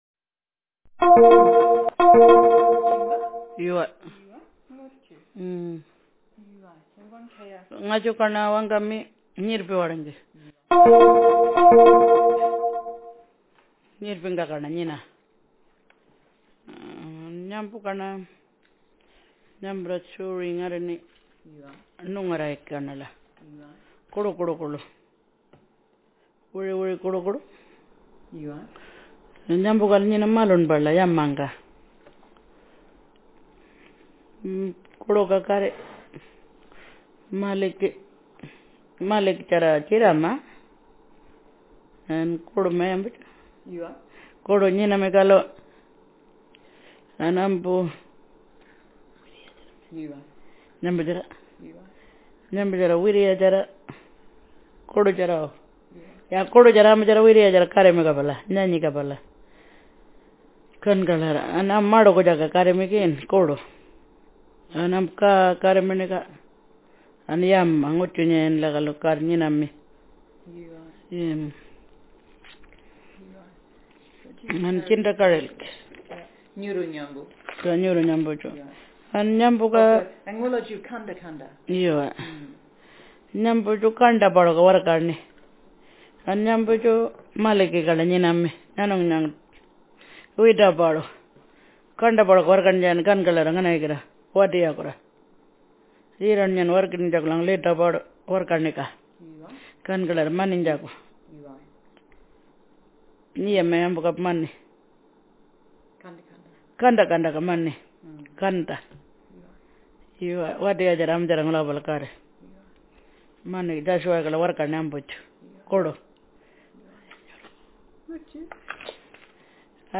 Speaker sex f Text genre stimulus retelling